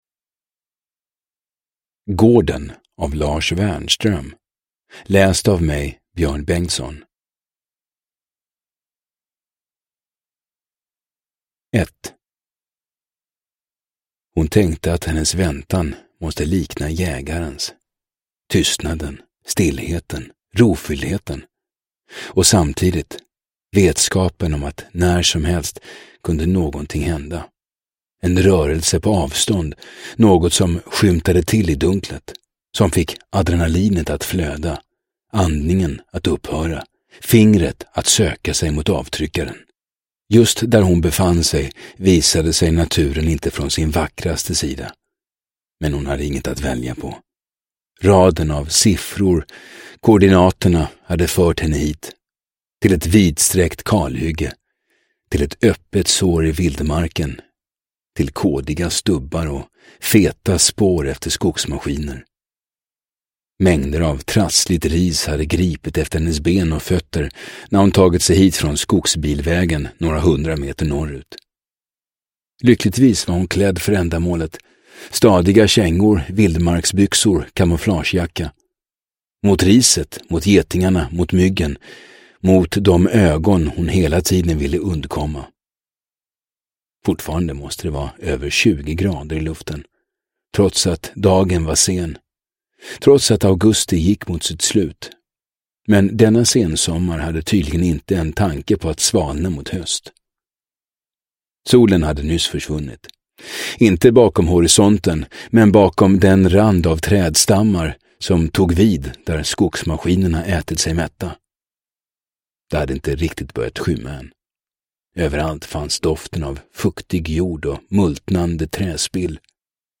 Gården – Ljudbok – Laddas ner